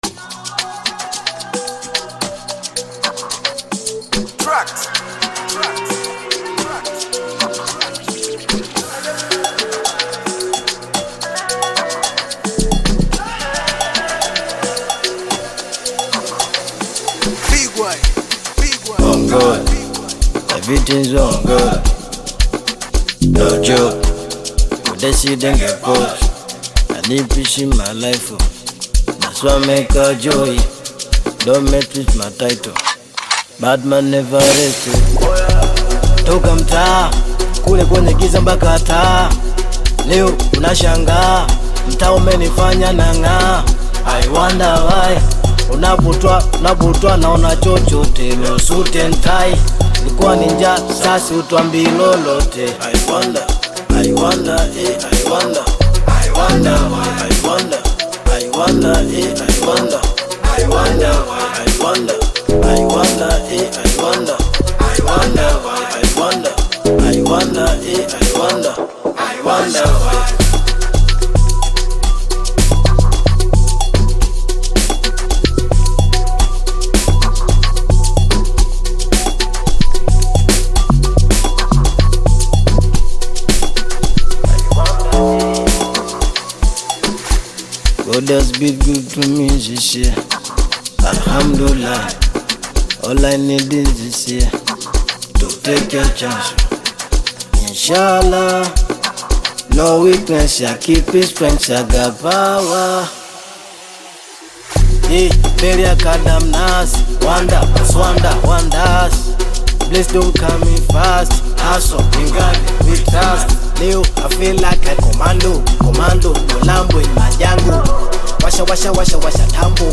Bongo Flava
singer and songwriter